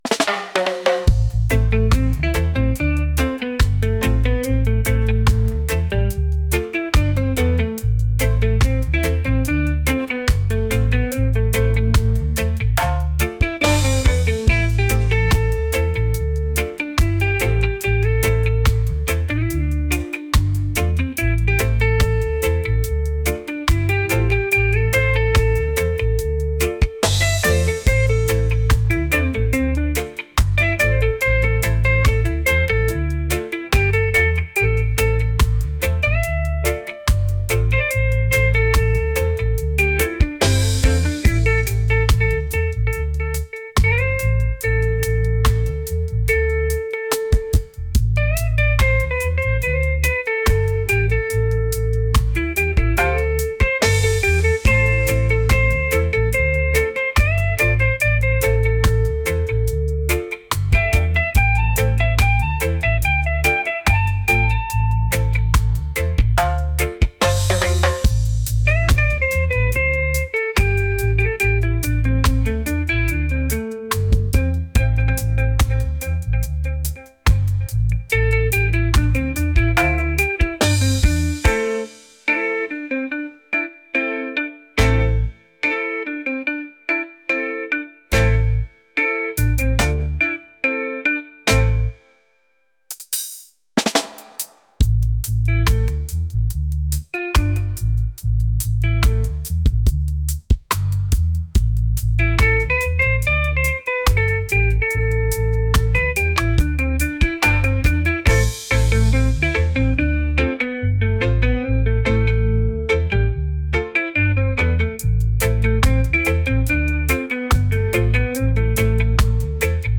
island | vibes | reggae